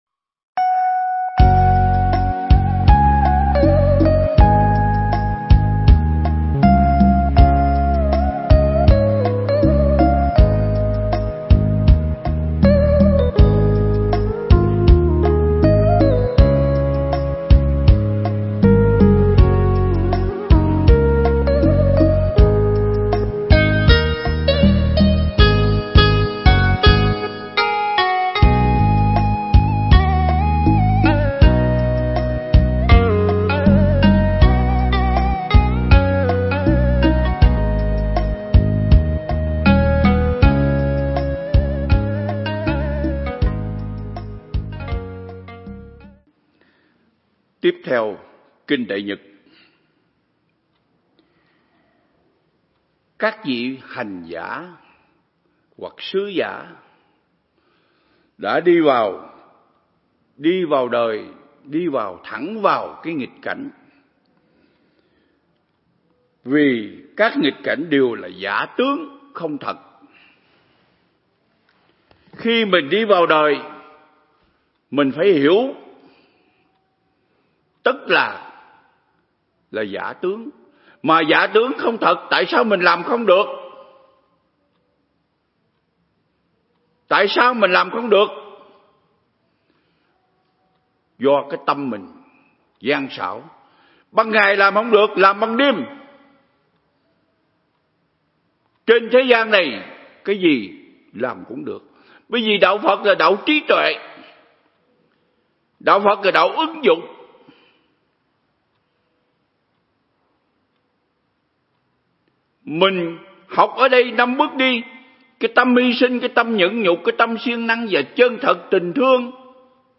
Pháp Thoại
giảng tại Viện Nghiên Cứu Và Ứng Dụng Buddha Yoga Việt Nam (TP Đà Lạt)